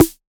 Index of /musicradar/retro-drum-machine-samples/Drums Hits/Tape Path B
RDM_TapeB_MT40-Snr01.wav